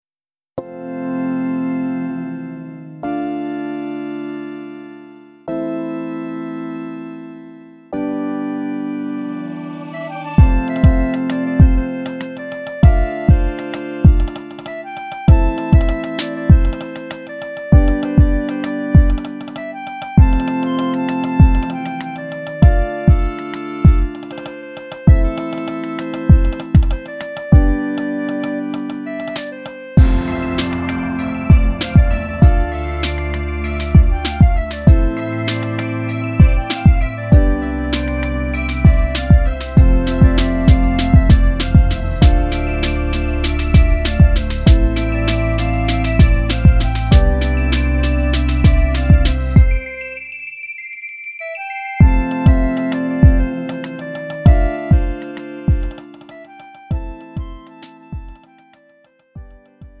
음정 원키 2:35
장르 가요 구분 Lite MR